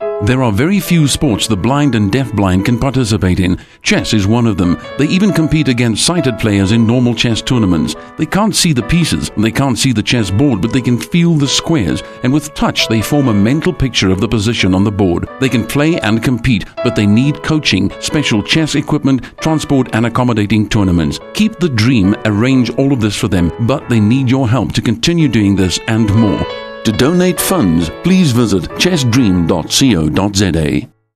Radio Ads
Keep The Dream 30sec Ad.mp3